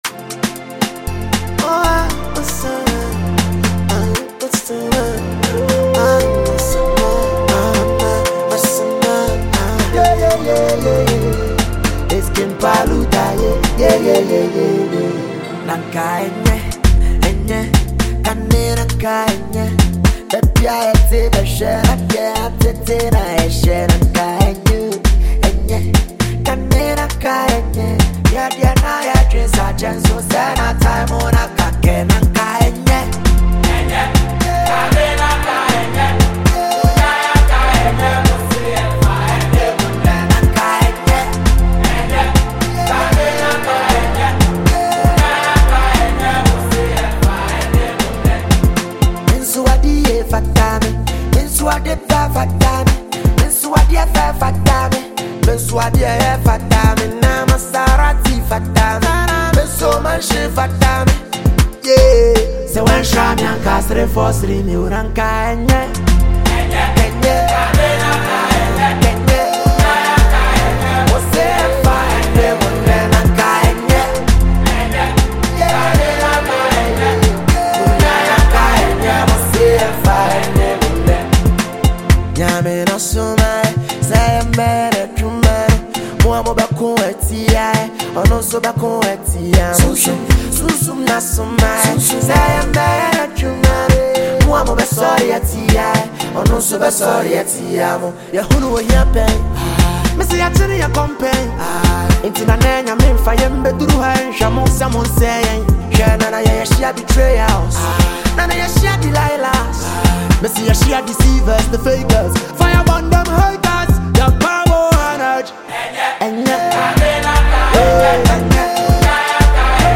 Multi-talented Ghanaian rapper and singer